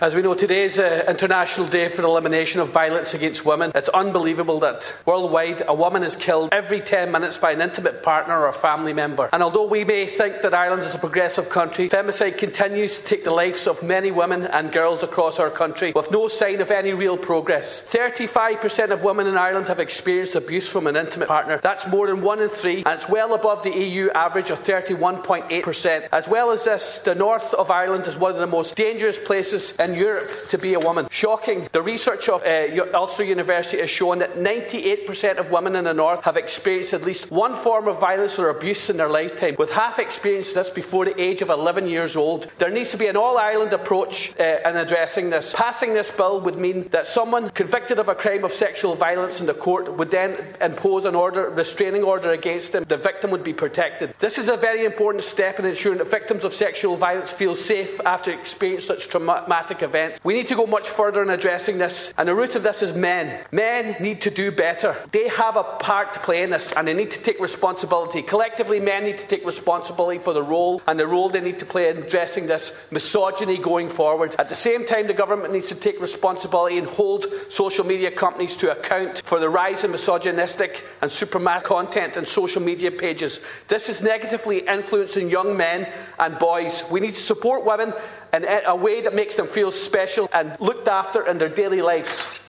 Deputy Ward told the Dáil last night that 1 in 2 women who have experienced violence in the north had done so in their childhood.